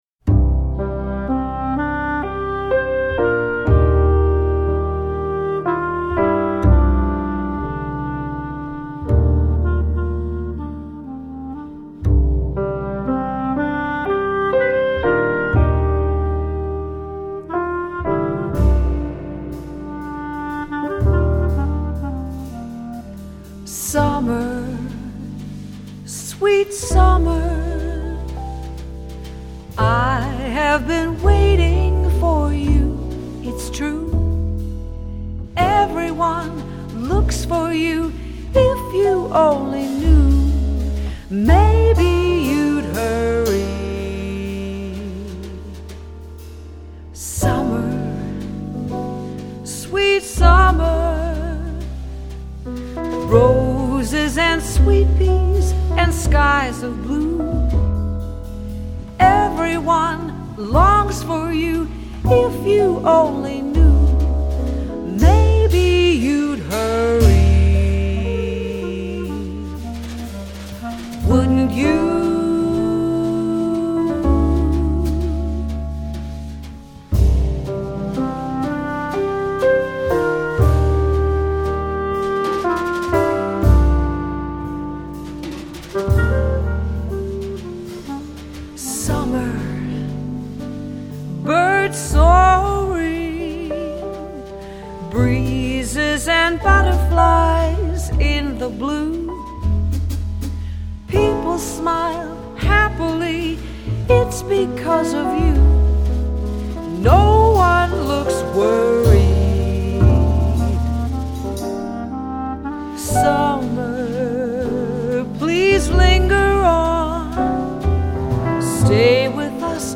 -   爵士及藍調 (293)
★ 明亮愉悅、充滿情感，最溫柔撫慰人心的樂章！
★ 頂級錄音打造清澈立體、圓潤滑順的動人歌聲！